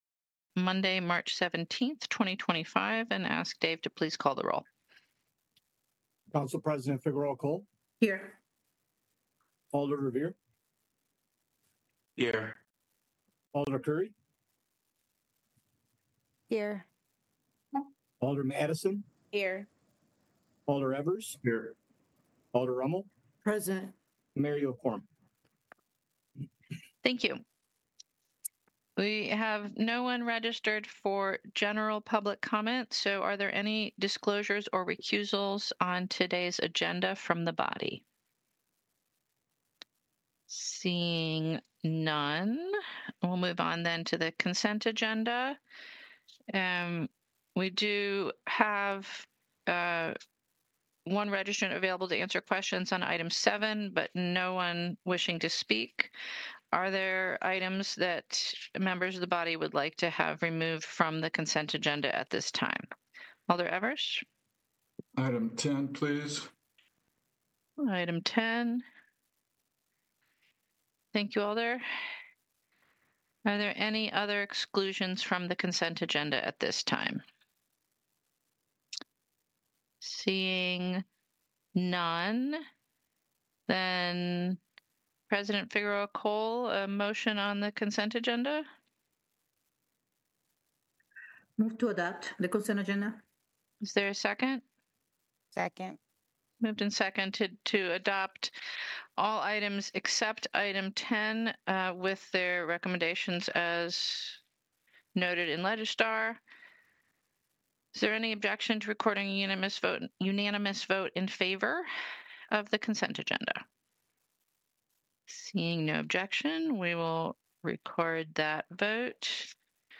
This is a podcast of City of Madison, Wisconsin Finance Committee meetings. The Finance Committee makes recommendations regarding budget amendments and other matters with significant fiscal implications during the year.